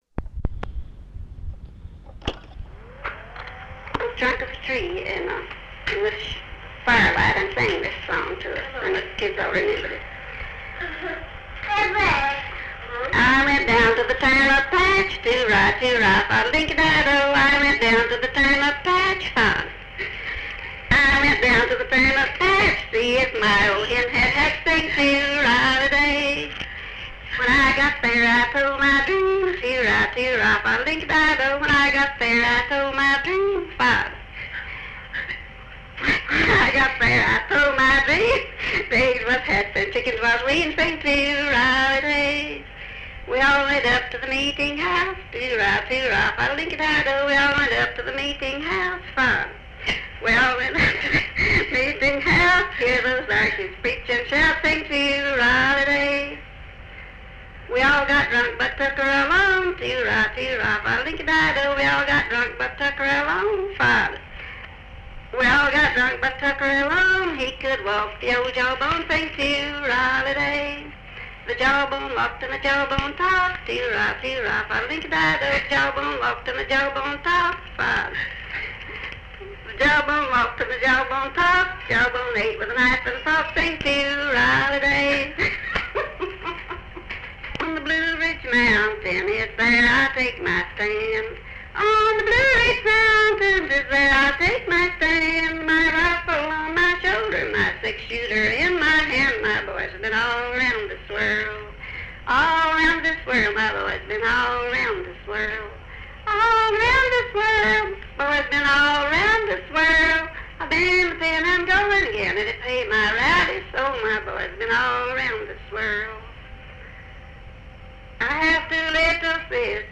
Recording Session